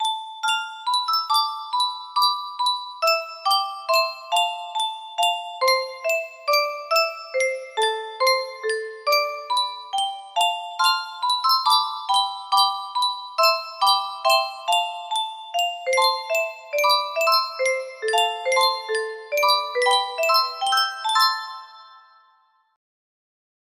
Roses And Petals music box melody